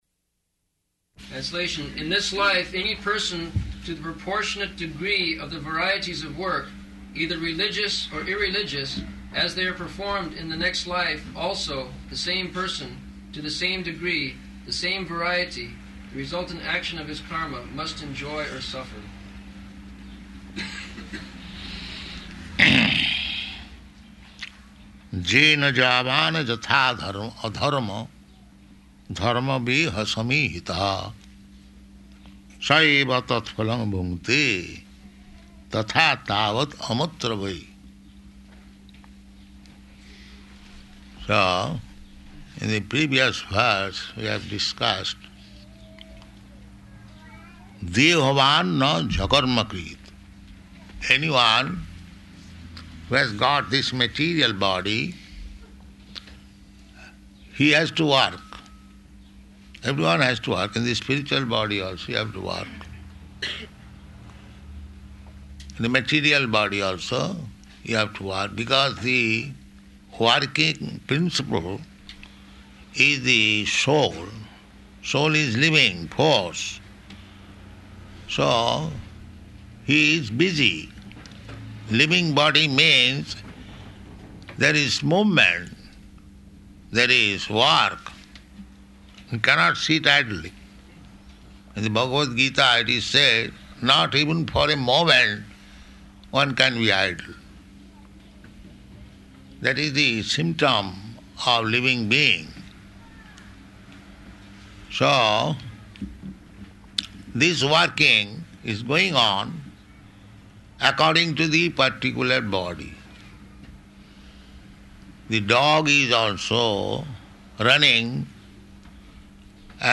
Location: Laguna Beach